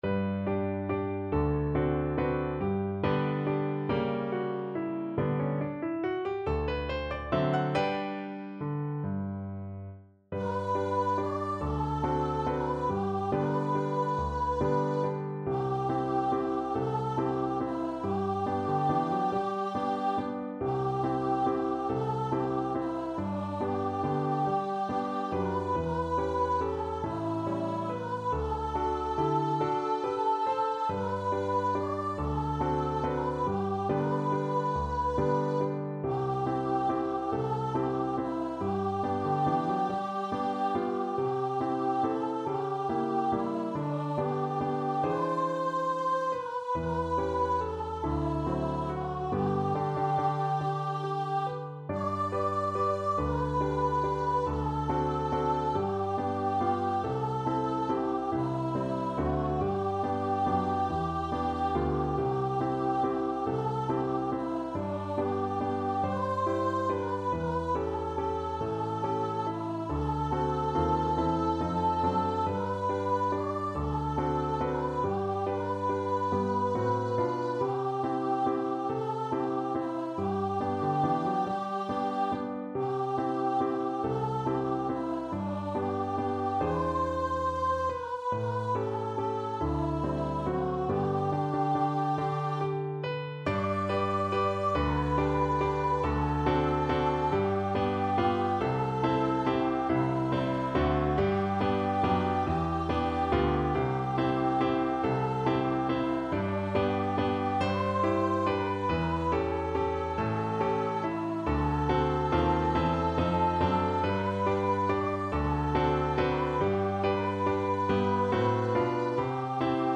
~ = 140 Tempo di Valse
Pop (View more Pop Voice Music)